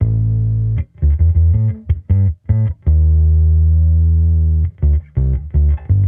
Index of /musicradar/sampled-funk-soul-samples/79bpm/Bass
SSF_PBassProc2_79A.wav